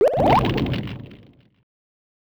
fire_gain.wav